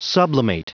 Prononciation du mot sublimate en anglais (fichier audio)
Prononciation du mot : sublimate